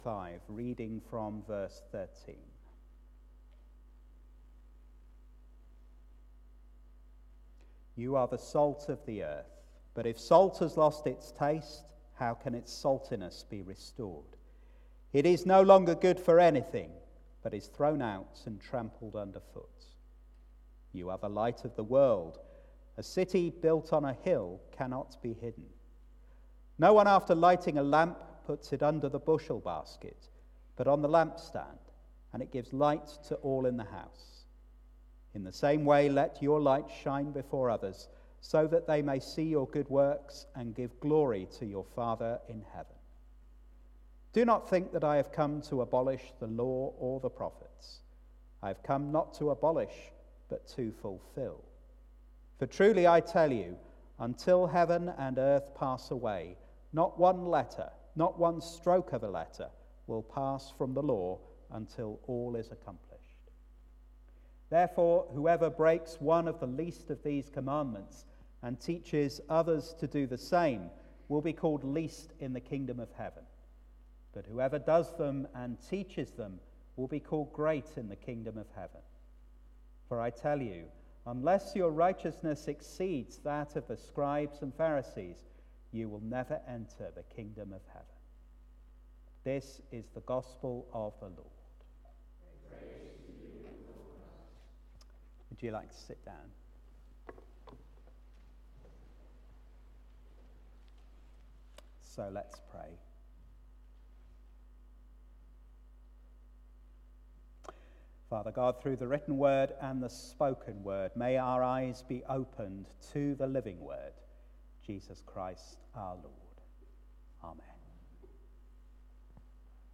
Sermon for the second Sunday of Advent